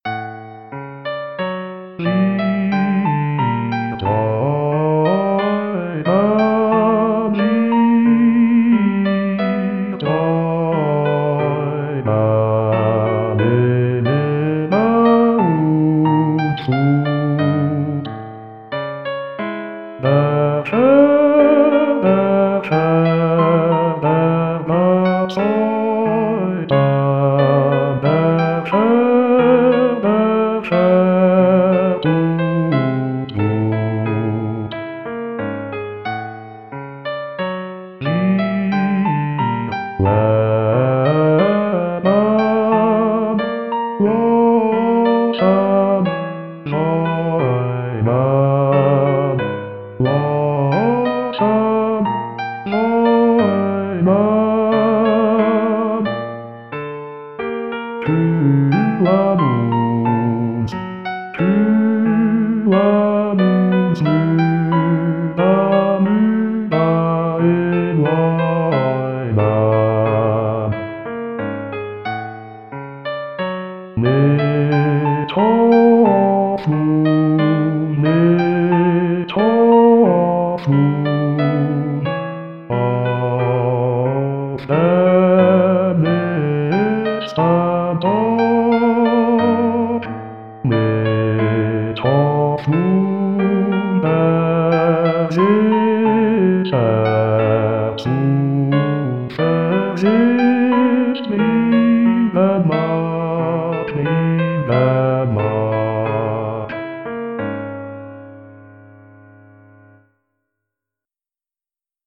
für Bass
mp3-Aufnahme: Wiedergabe Bass Gesang